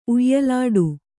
♪ uyyalāḍu